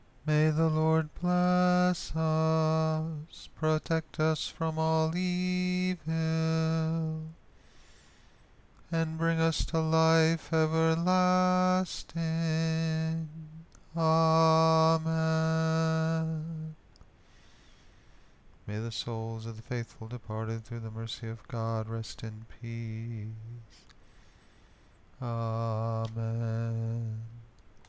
Caveat: this is not Gregorian Chant.